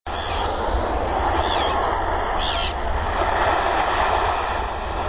Laughing Gull
Laughing Gull cries as a flock follows a large school of small fish up and down the shoreline.  Laughing Gulls dive for fish in a Tern-like manner but from less of a height.  7/9/04, in front of Jenkinson's and Martell's Tiki Bar, 7:00 p.m. (20kb)
gull_laughing_851.wav